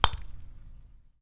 putt1.wav